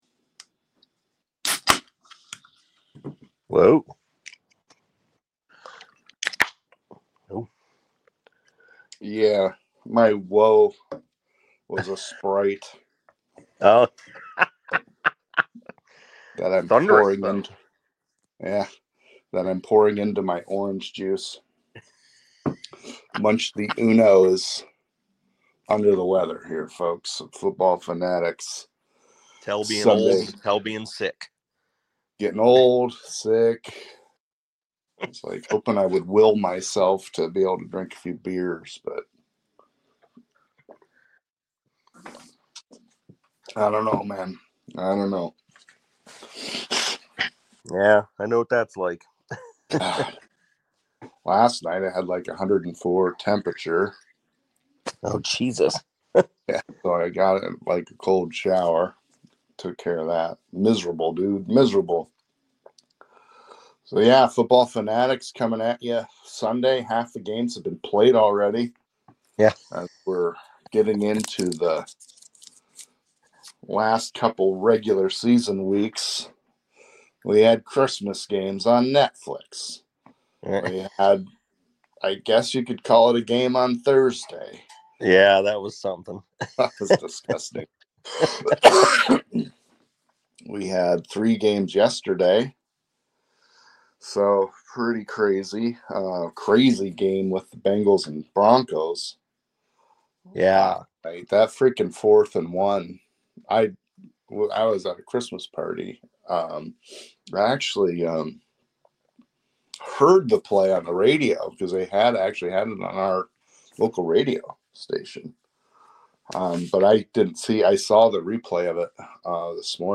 One of the shortest podcasts in a long time, under an hour, a smaller slate of games and only two of us the show went quickly